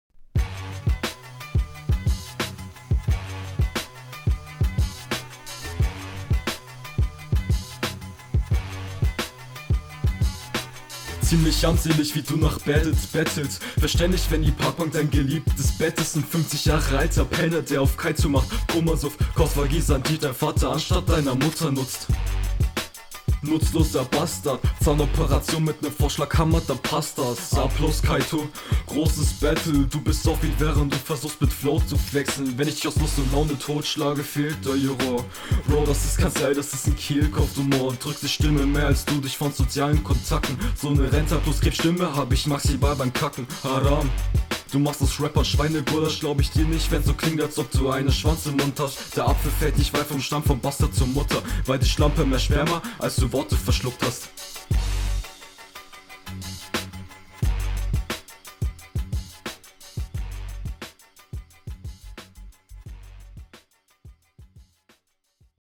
Könnte noch etwas mehr Kraft rein. Klingt aber recht lässig.